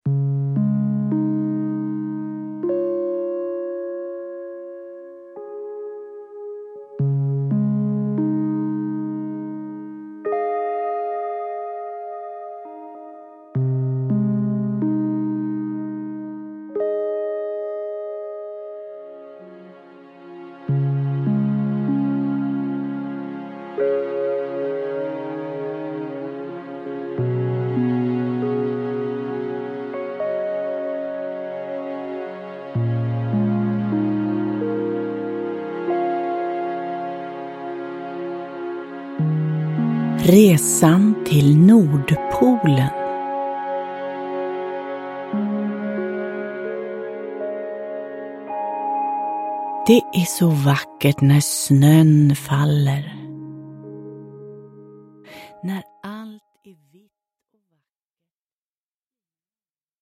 Resan till Nordpolen – Ljudbok – Laddas ner